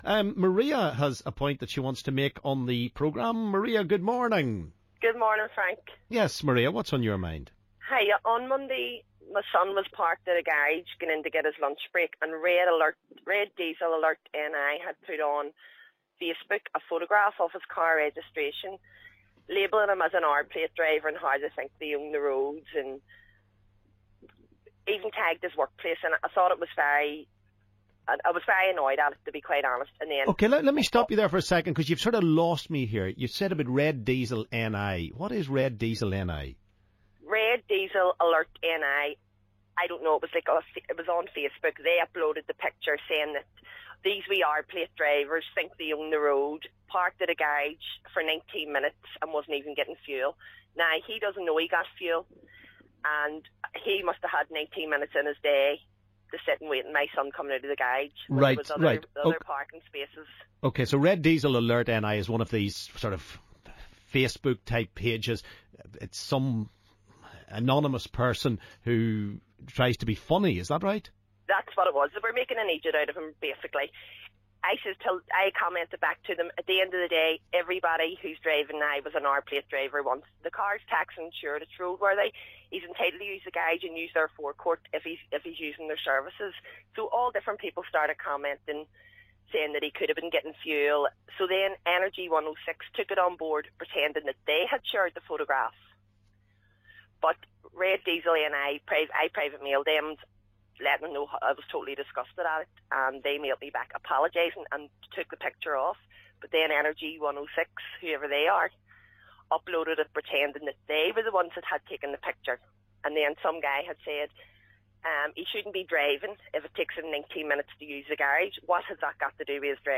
LISTEN ¦ Caller tells us about her sons petrol station predicament...